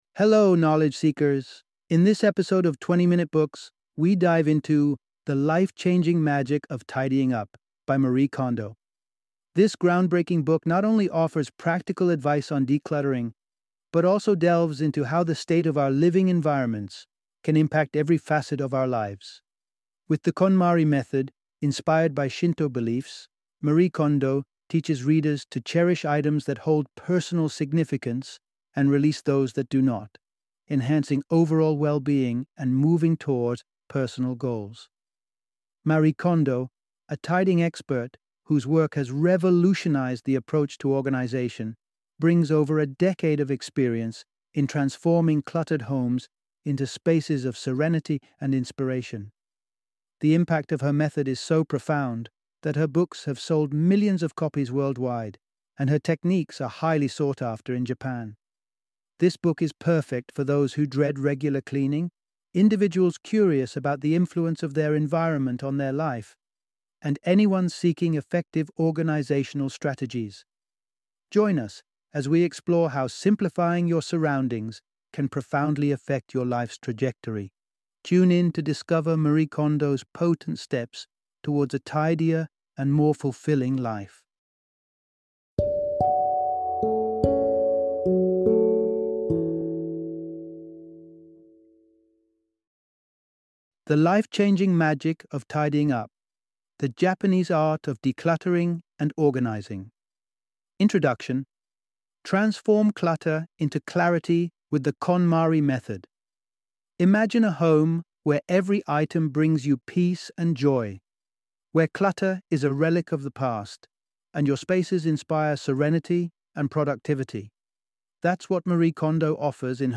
The Life-Changing Magic of Tidying Up - Audiobook Summary